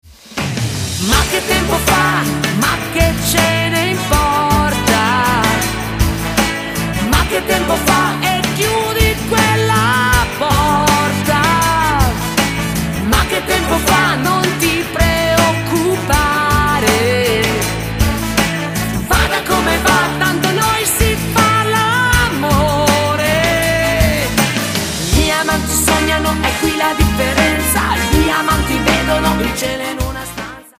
MODERATO  (3.44)